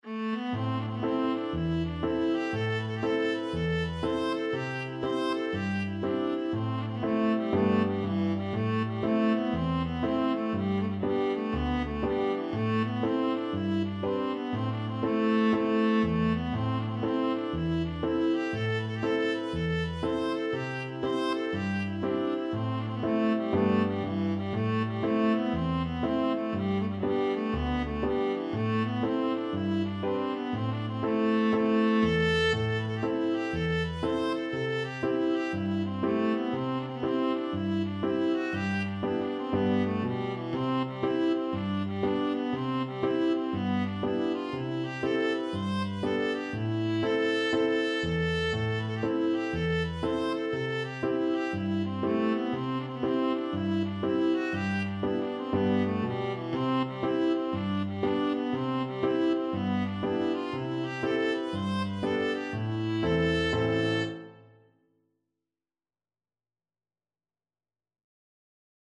Viola
Allegro (View more music marked Allegro)
G major (Sounding Pitch) (View more G major Music for Viola )
4/4 (View more 4/4 Music)
Traditional (View more Traditional Viola Music)